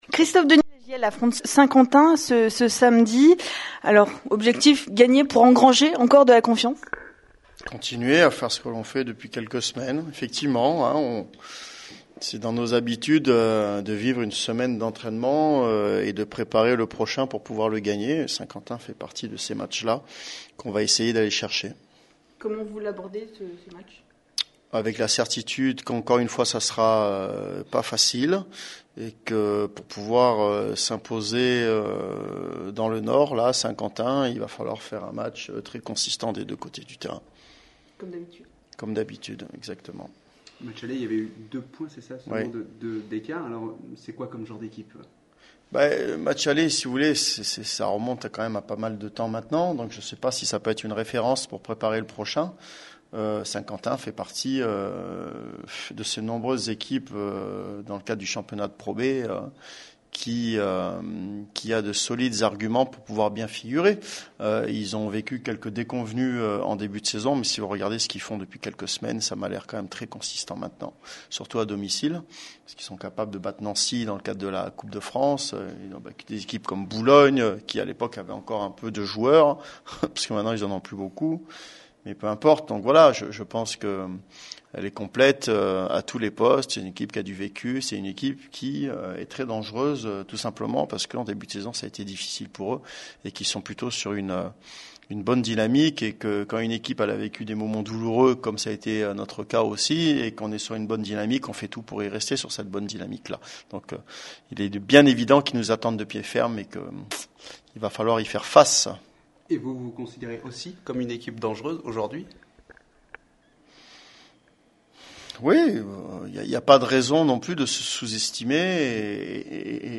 Ce jeudi 31 mars 2016, la JL Bourg organisait sa traditionnelle conférence de presse d’avant-match.